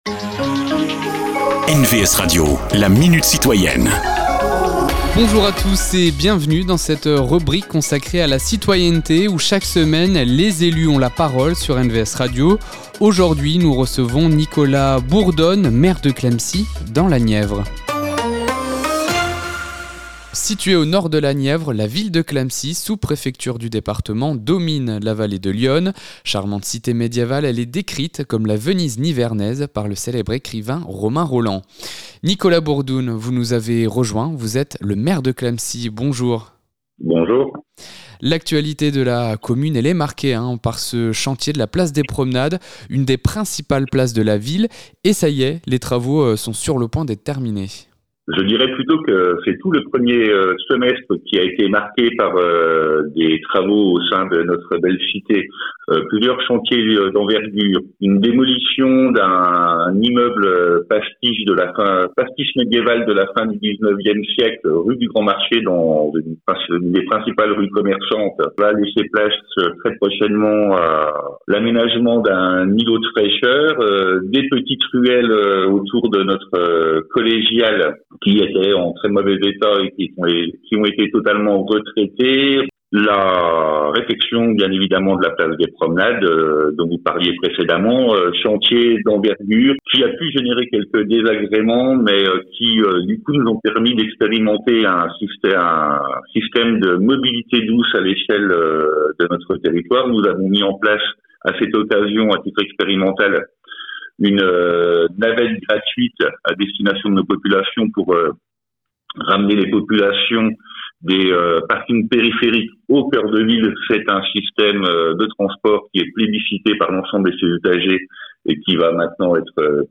Cette semaine, Nicolas Bourdoune, Maire de Clamecy.